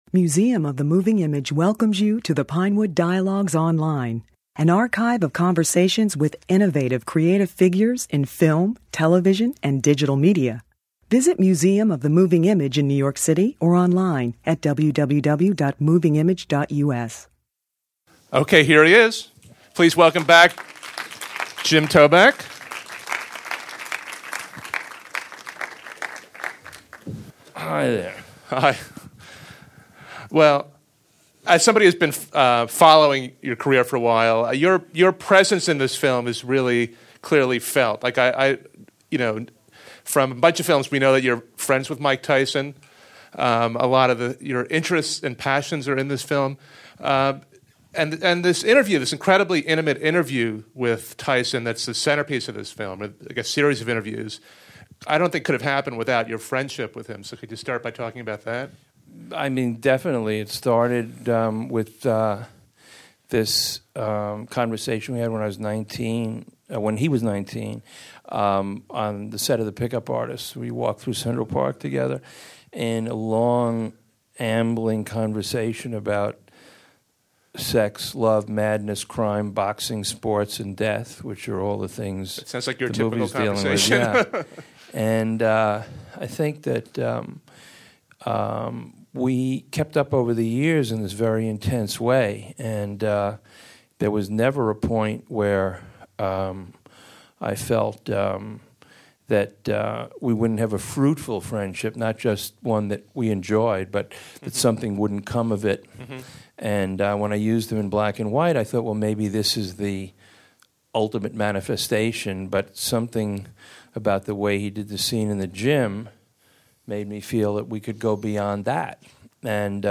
Toback discussed the film at a special preview screening for the Museum of the Moving Image. Please note that some of the audience questions are inaudible.